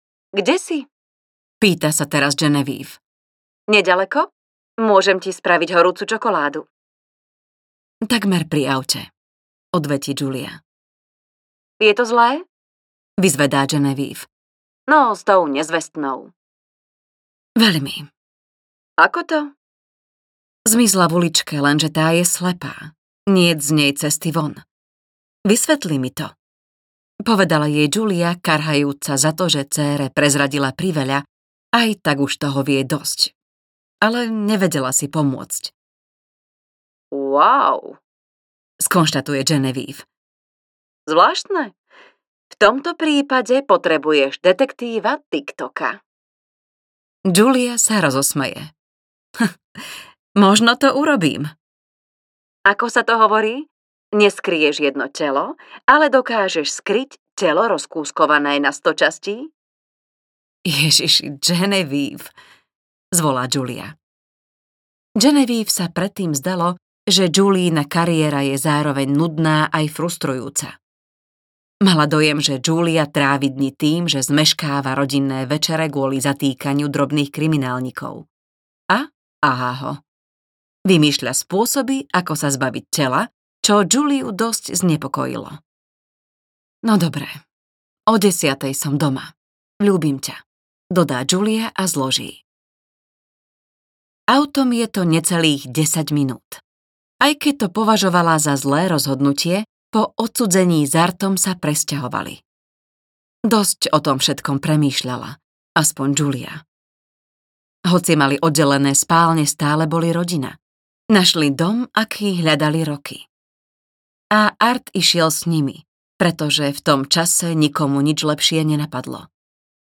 Ďalšia nezvestná audiokniha
Ukázka z knihy